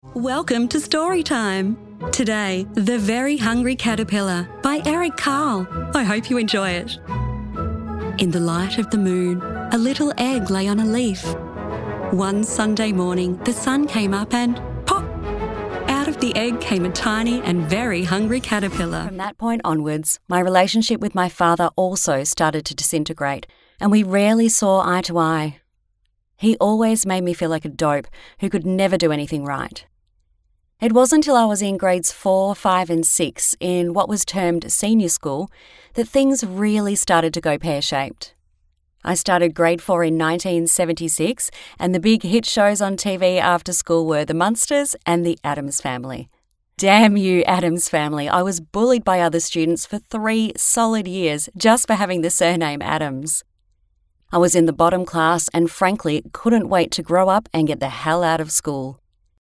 Narrator for Audio Books | Foreign Voice Talent
My voice overs are confident, warm, conversational, expressive, engaging, versatile and clear.
0208Audiobook_Narration_Demo.mp3